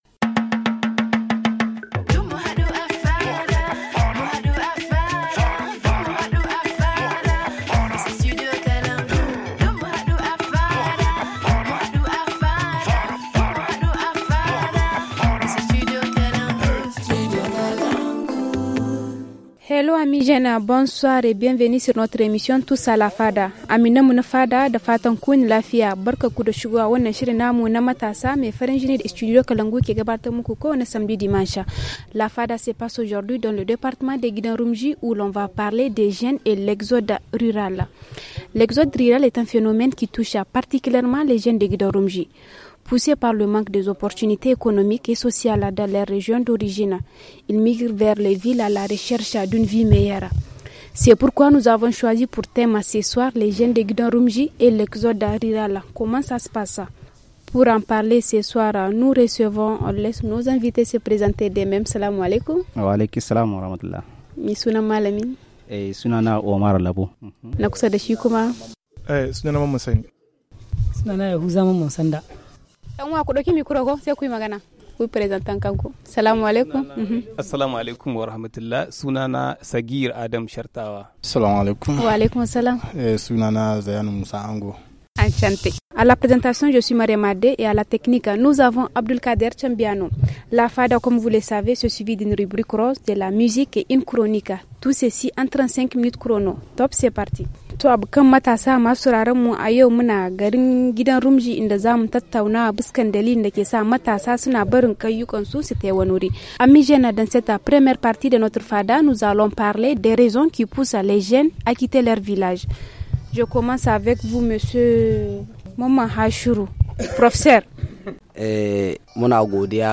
Notre émission Tous à la Fada se passe aujourd’hui dans le département de Guidan Roumdji ou l’on va parler des jeunes et l’exode rural.